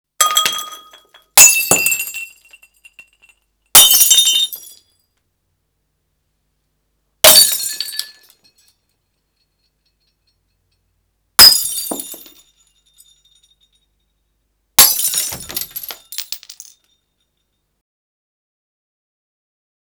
01.打碎玻璃声效.wav